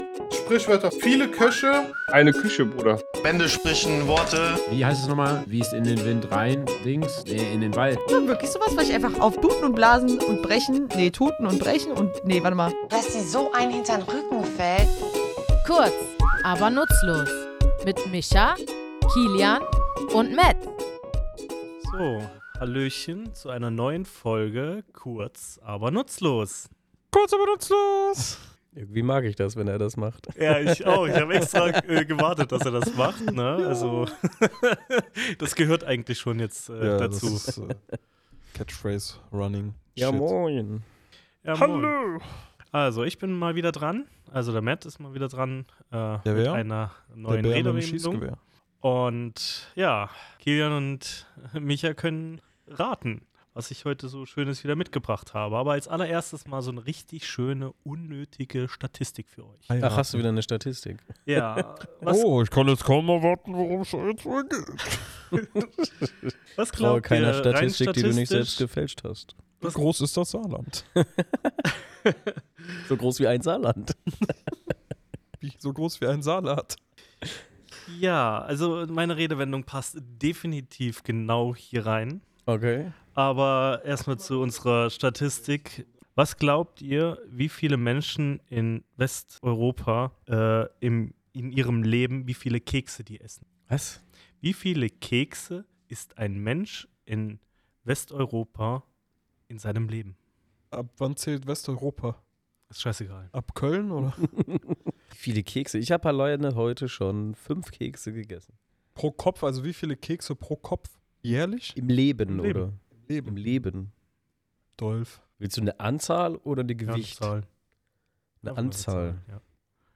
Wir, drei tätowierende Sprachliebhaber, plaudern in unserem Tattoostudio über die Herkunft, Bedeutung und die humorvolle Seite dieser Redensart.